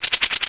chatter1.wav